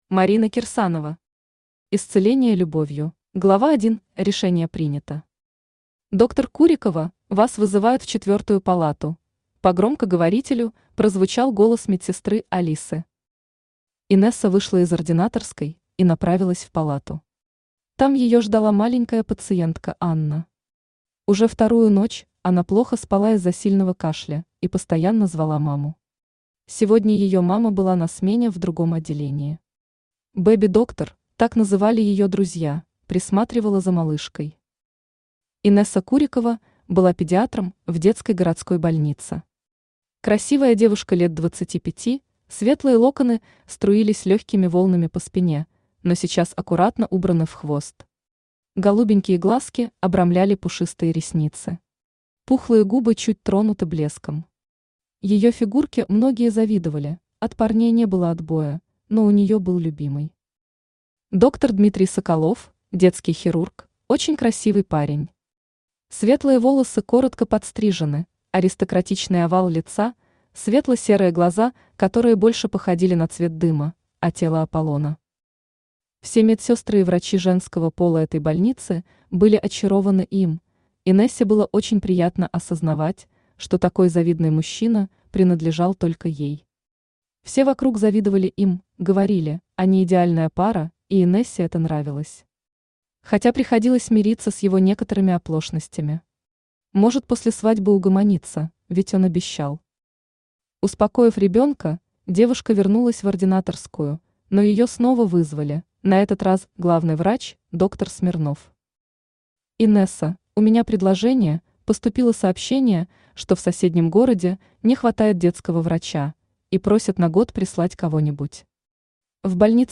Аудиокнига Исцеление любовью | Библиотека аудиокниг
Aудиокнига Исцеление любовью Автор Марина Кирсанова Читает аудиокнигу Авточтец ЛитРес.